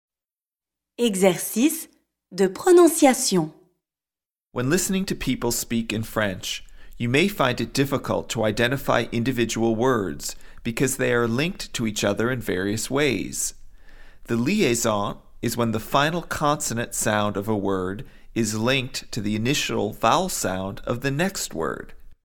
PRONONCIATION
A “liaison” occurs when the final consonant sound of one word is linked to the initial vowel sound of the next word.